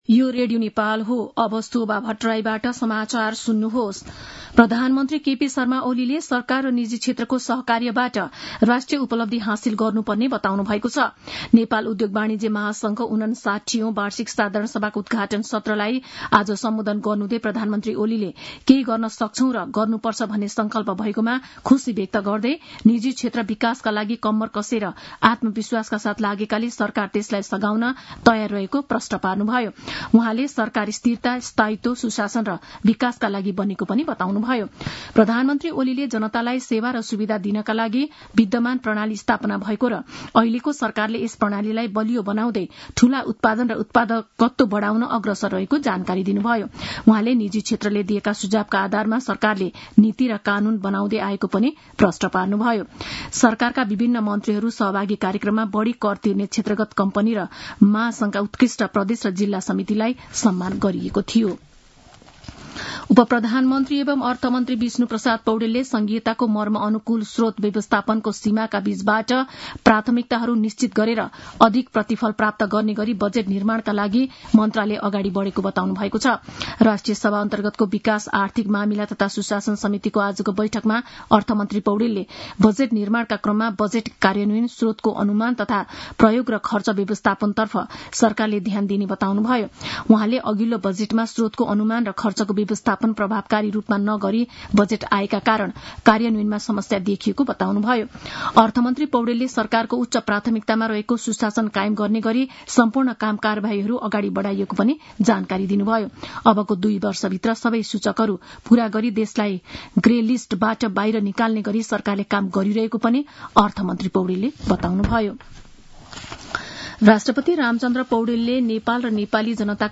दिउँसो ४ बजेको नेपाली समाचार : २८ चैत , २०८१
4-pm-news-1-2.mp3